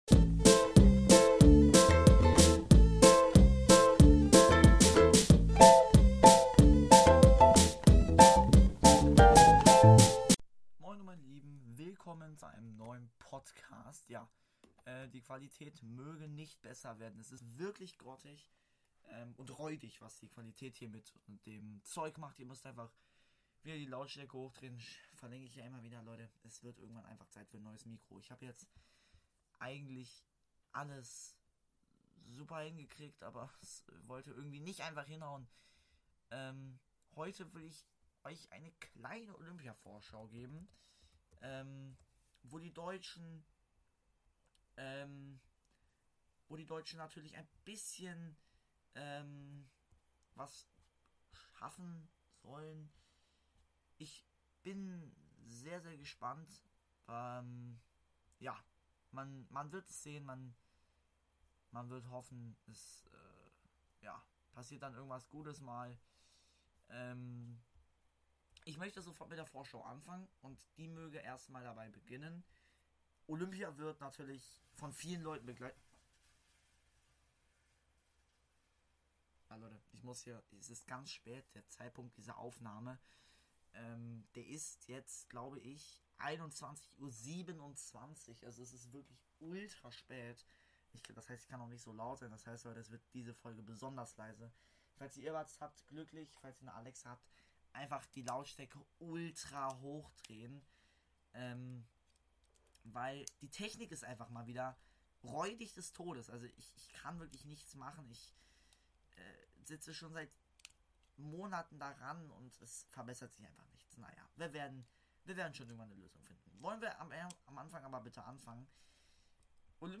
Hallo meine Lieben, die Technik spinnt mal wieder, einfach die Lautstärke hochdrehen. Heute die Olympia-Vorschau.